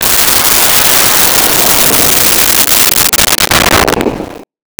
Missle 01
Missle 01.wav